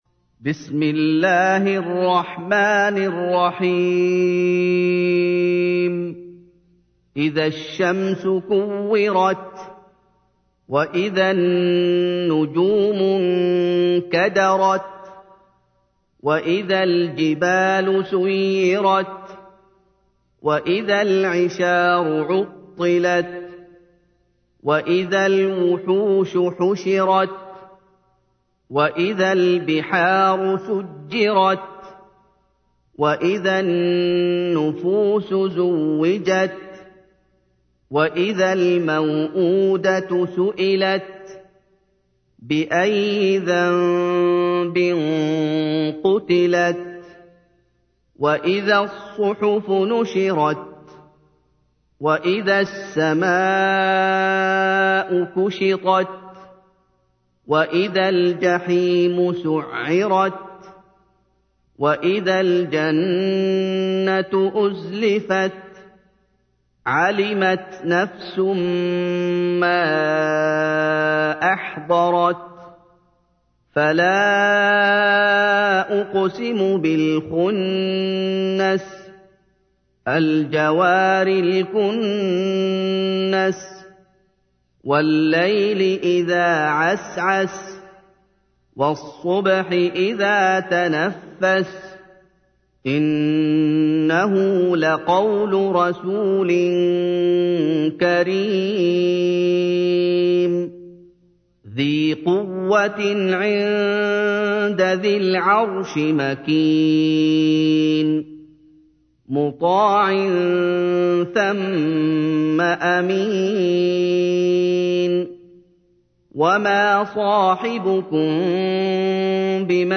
تحميل : 81. سورة التكوير / القارئ محمد أيوب / القرآن الكريم / موقع يا حسين